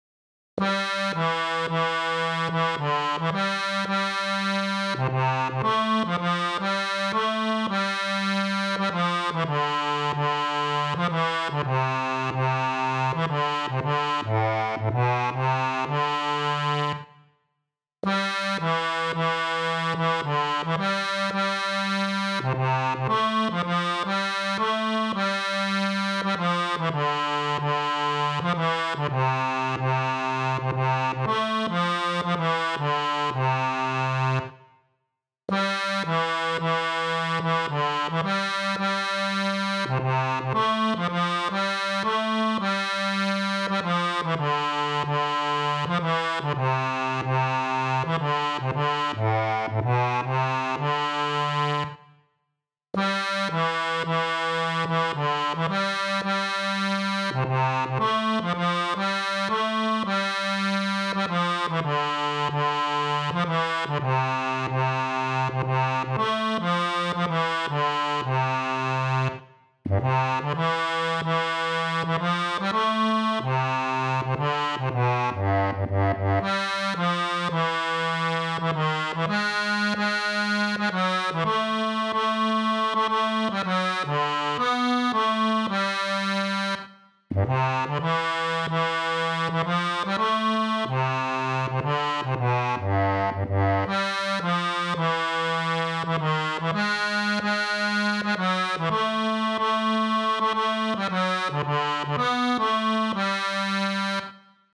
Chants de marins